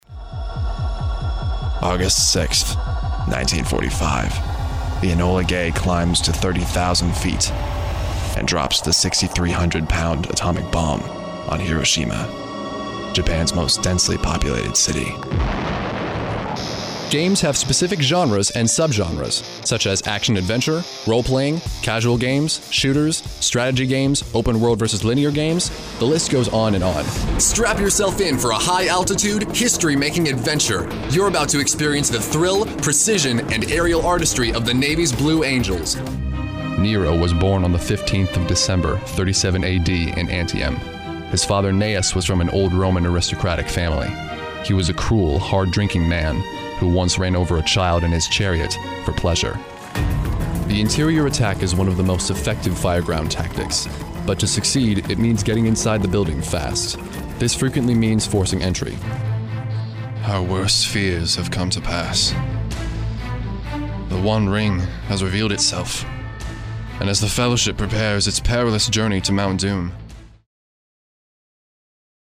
Male
Genuine youthful sound, but with range in more mature sounds as well.
Documentary
Friendly / Serious / Fun
Words that describe my voice are Youthful, Friendly, Conversational.